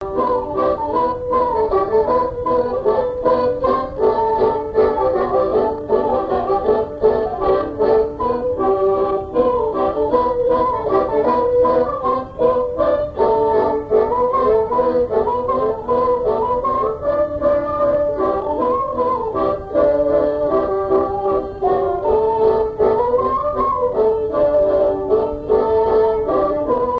danse : mazurka ; danse : java
Pièce musicale inédite